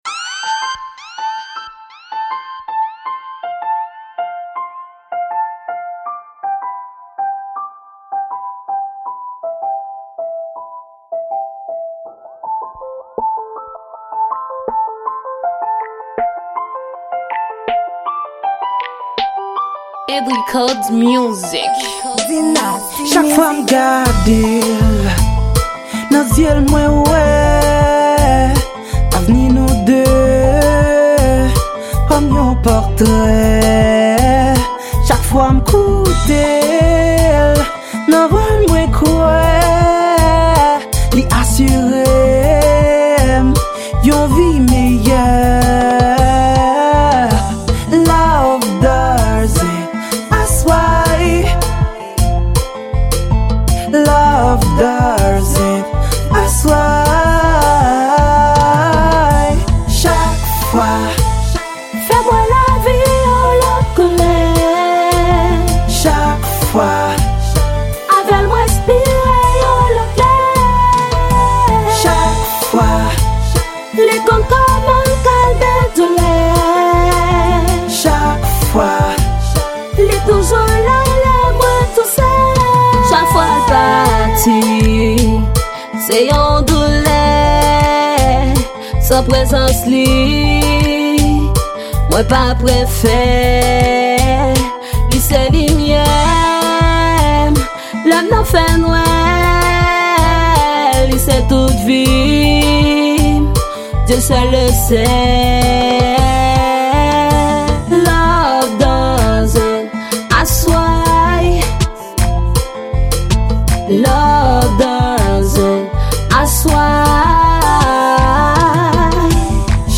Genre: Zouk.